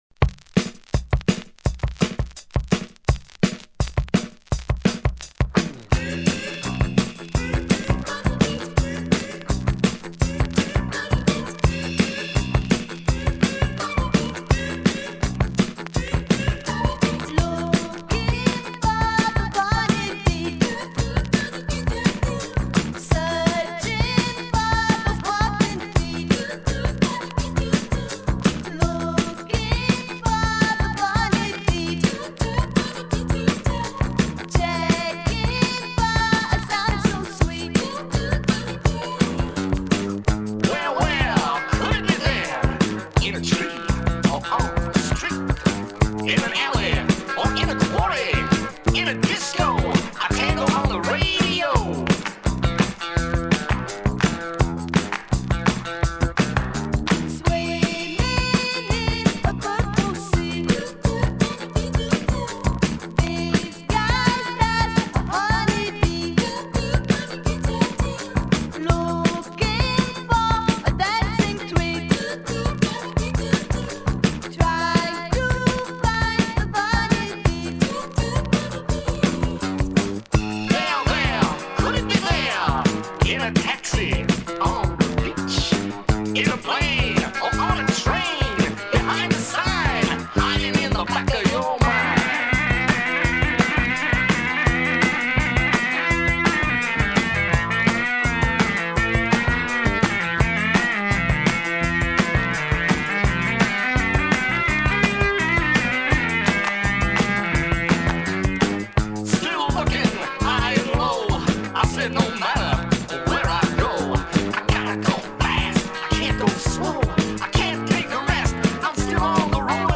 But at 45 RPM, it's a new wave treat!
Now, I'll leave you to enjoy this funky piece of happiness: